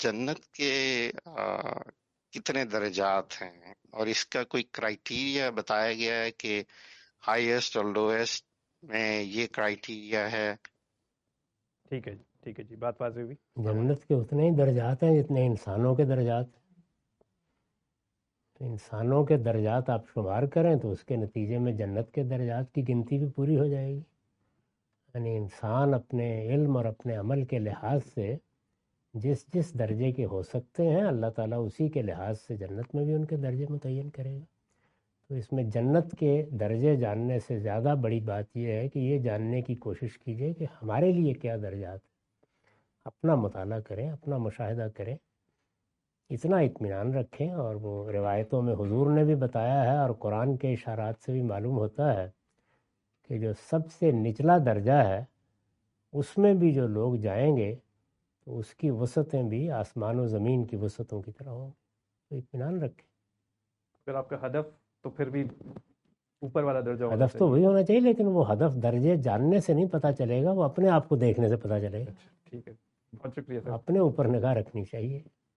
Category: Reflections / Questions_Answers /
In this video, Mr Ghamidi answer the question about "How many levels are there in Paradise?".